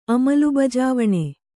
♪ amalubajāvaṇe